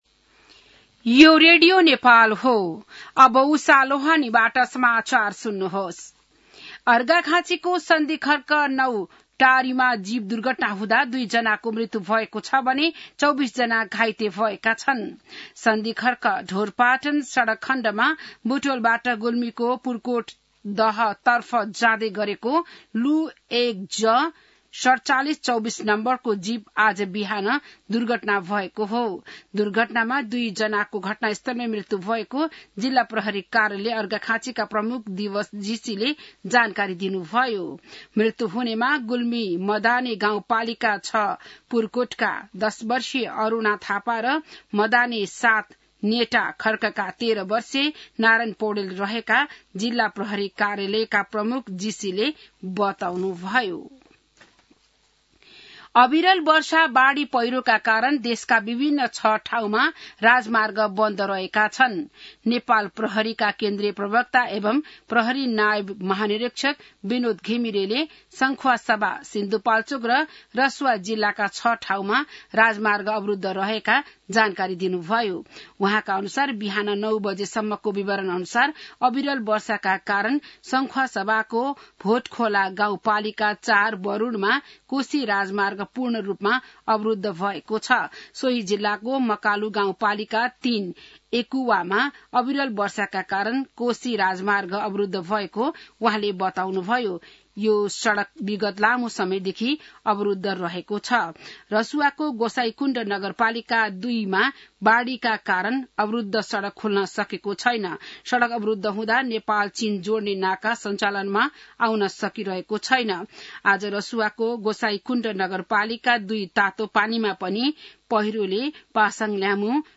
बिहान १० बजेको नेपाली समाचार : १३ असोज , २०८२